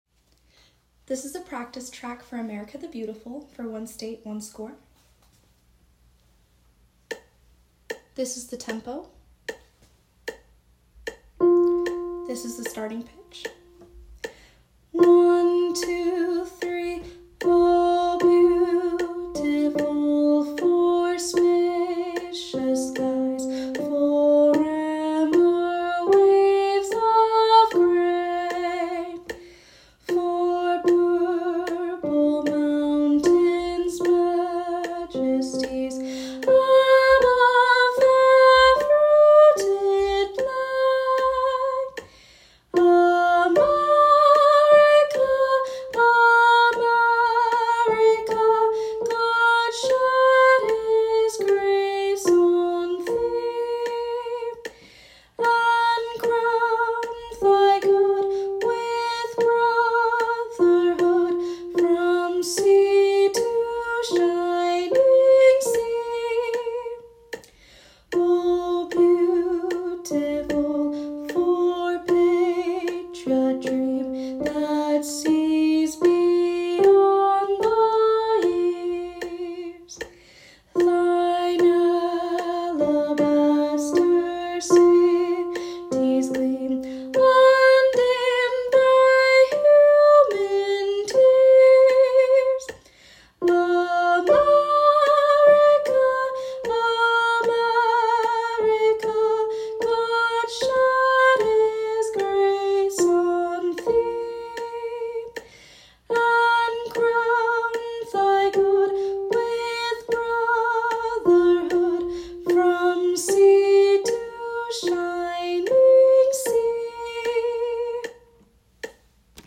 • Set your metronome to 76 bpm.
America-the-Beautiful-Practice-Track-One-State-One-Score.m4a